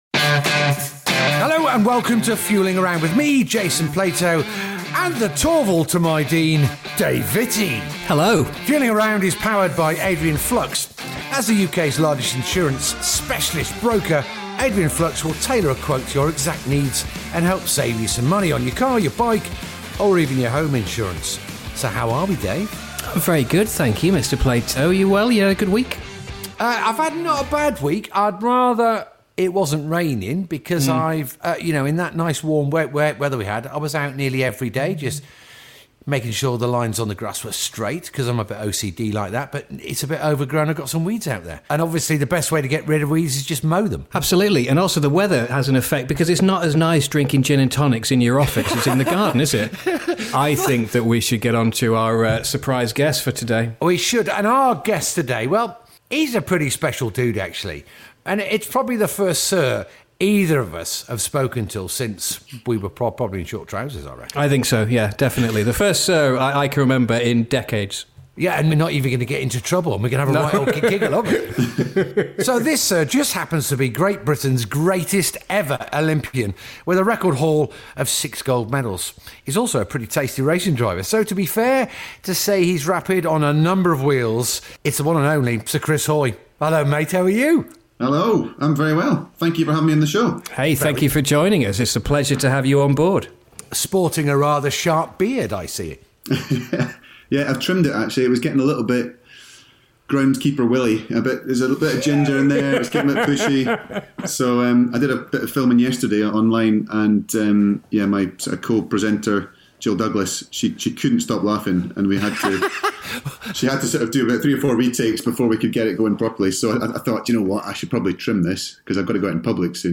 This episode was recorded remotely.